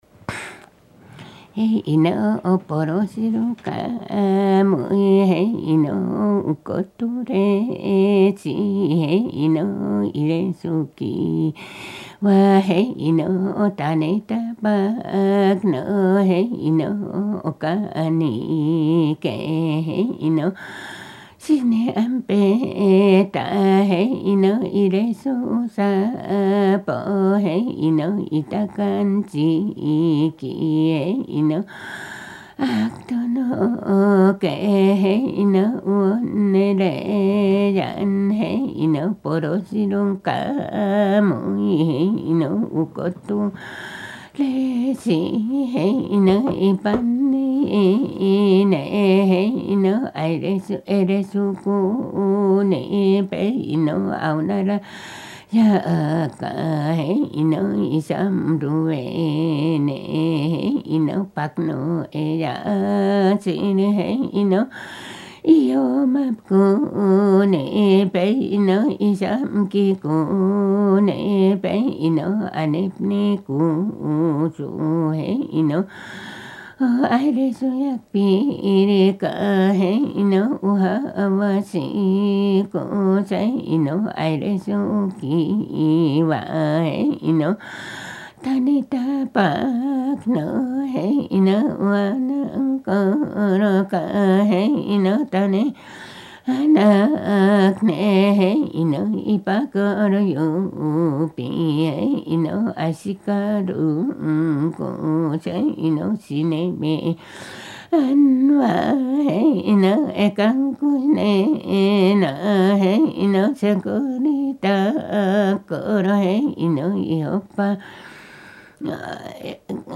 [23-4 神謡 mythic epics]【アイヌ語】9:00